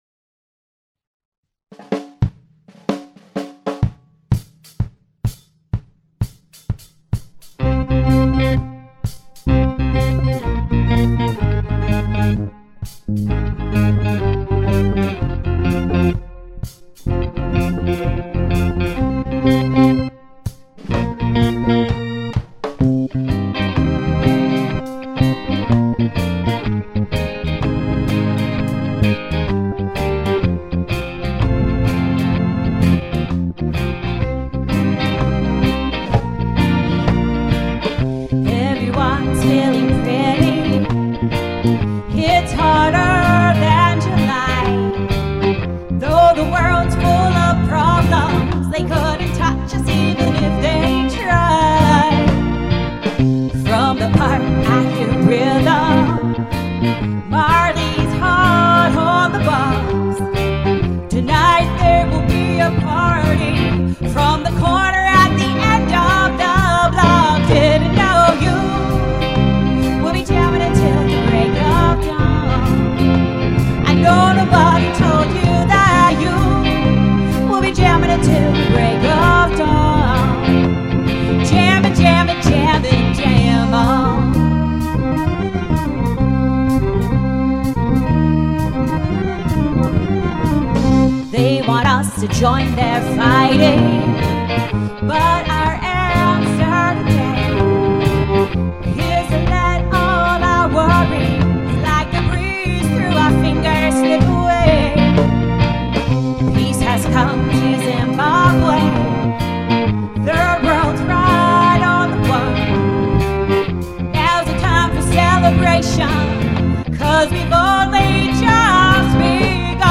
first studio album
bass
Reggae